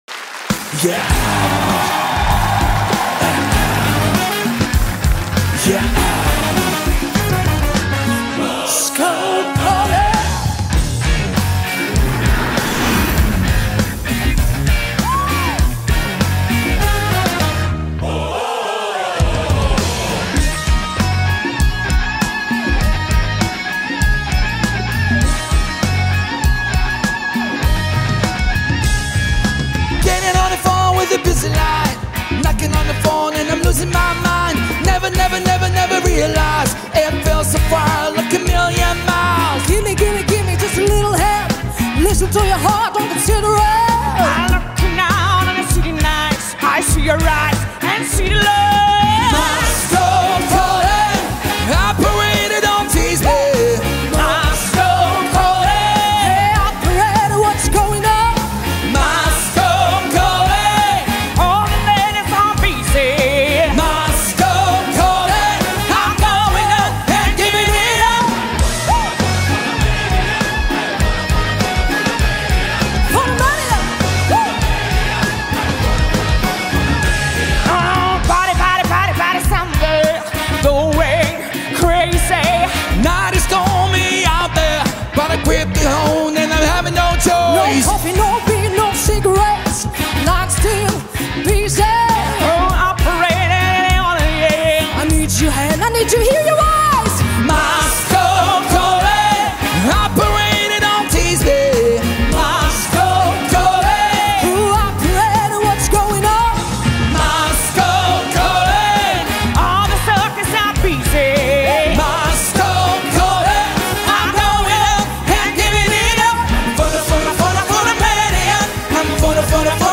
Гала-концерт от 06.12.2024г.